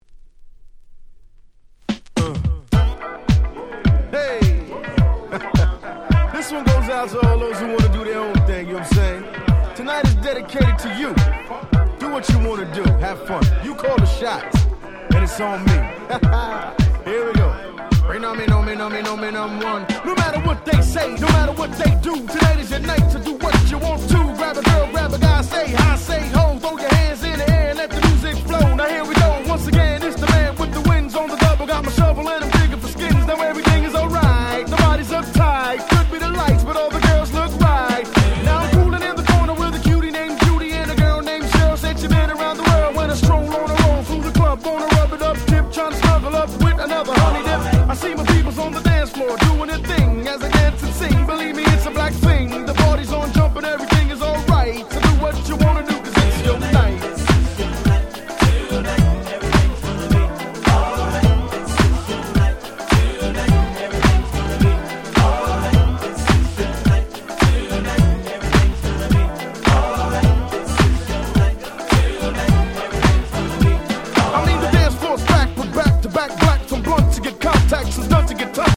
94' Nice Hip Hop !!
New Jackな感じの楽曲とも非常に合います！
Boom Bap ブーンバップ